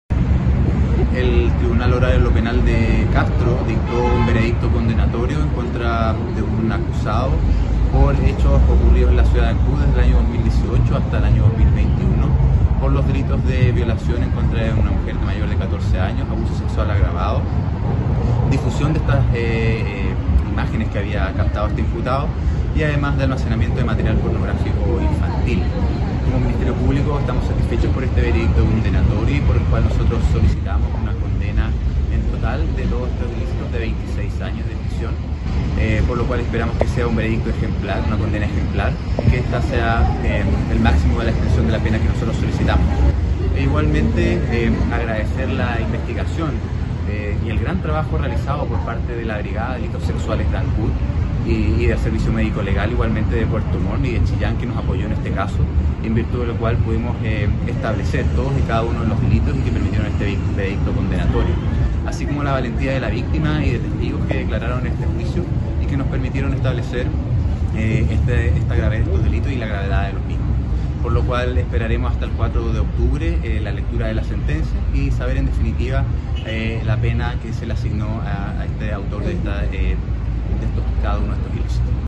Los detalles acerca de esta decisión del Tribunal Oral en Lo Penal de Castro la entregó el fiscal de Ancud, Luis Barría.